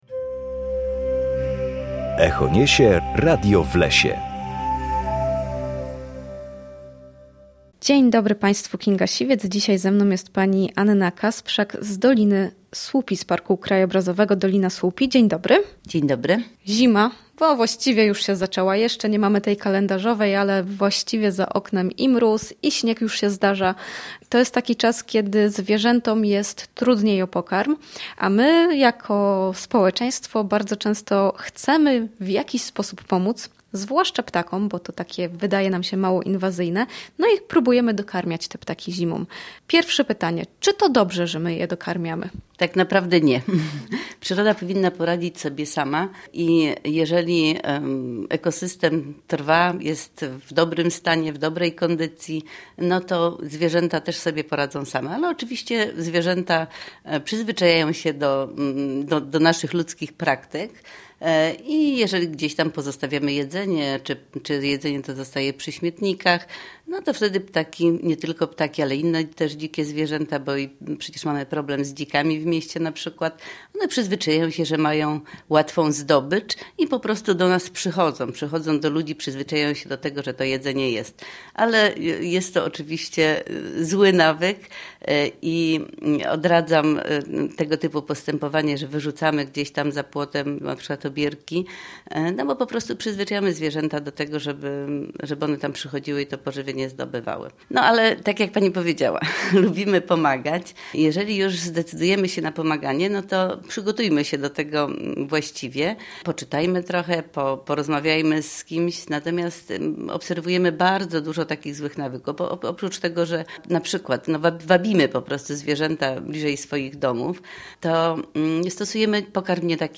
W każdą środę o godzinie 7:20 oraz o godzinie 14:10 na antenie Studia Słupsk rozmawiamy o naturze i sprawach z nią związanych.